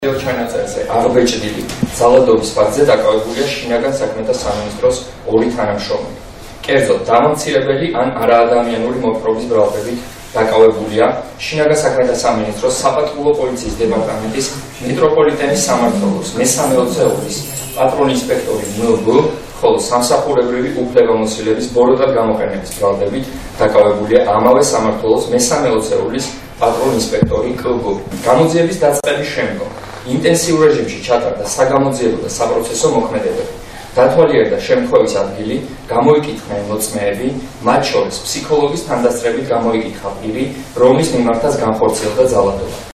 სახელმწიფო ინსპექტორის პირველი მოადგილე გიორგი გამეზარდაშვილი